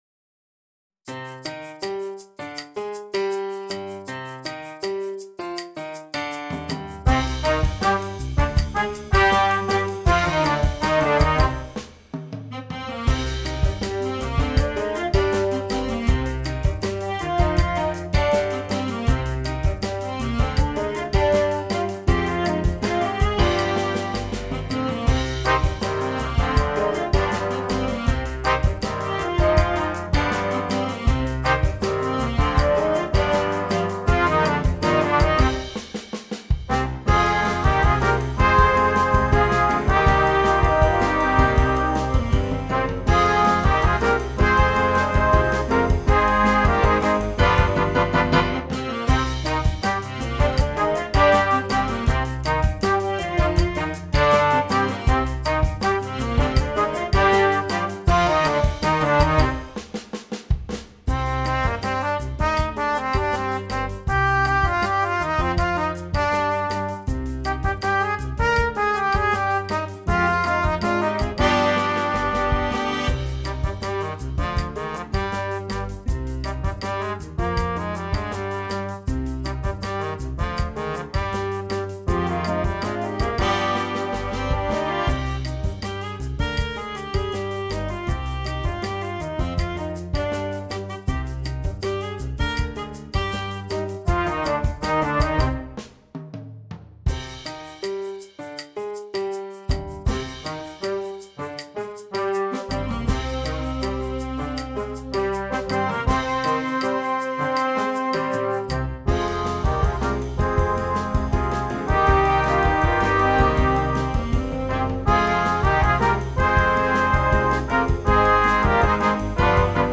Jazz Band